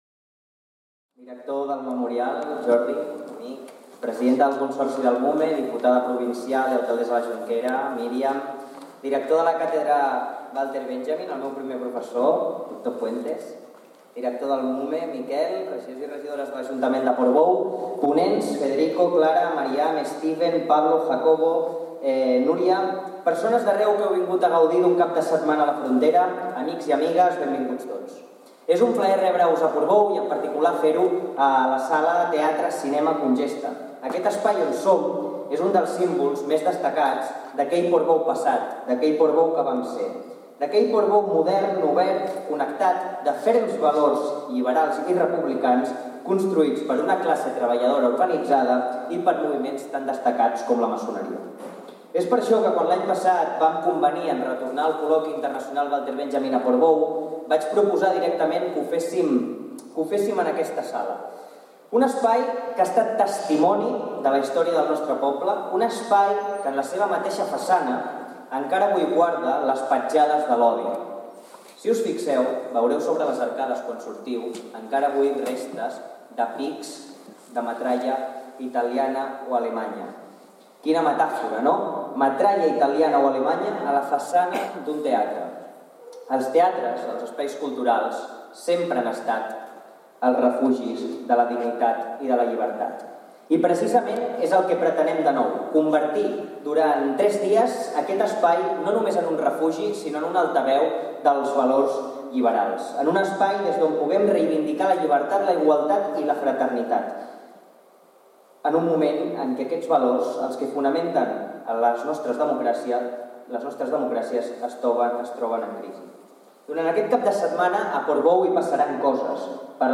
Presentacions institucionals